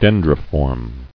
[den·dri·form]